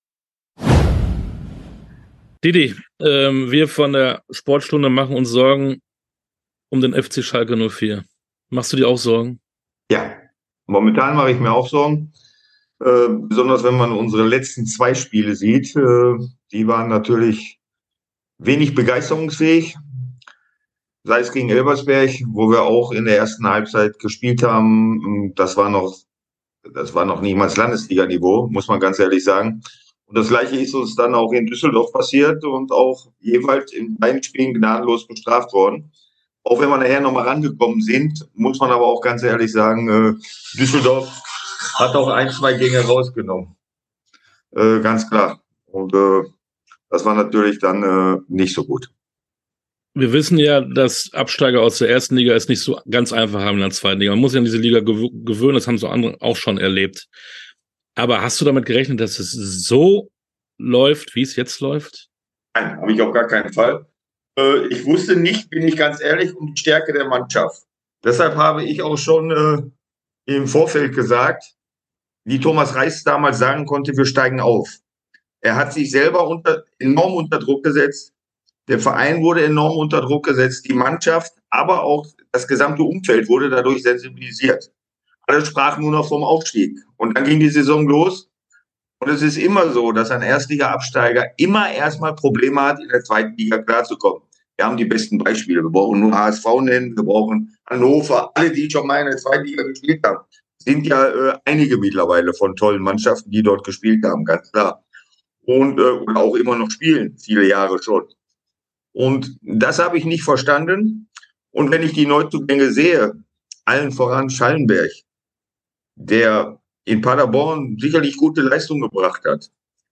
Interviews in voller Länge